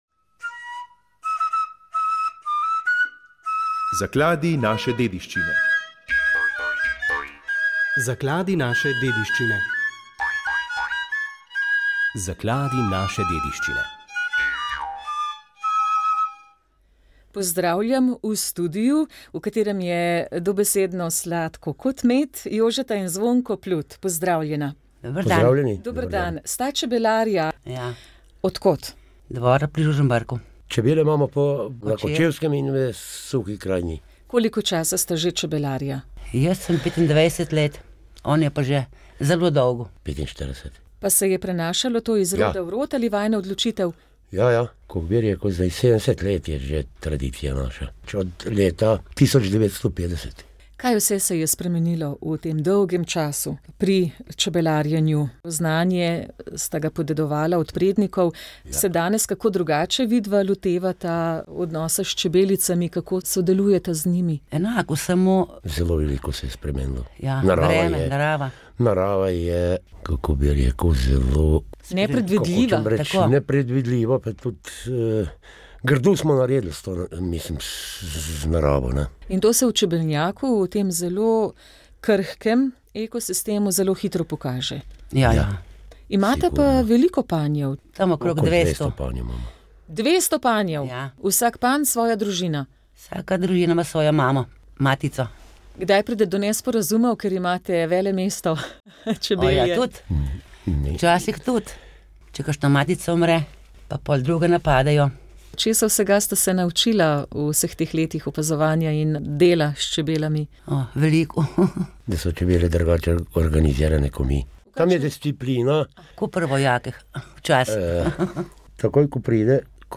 Med sodelujočimi na okrogli mizi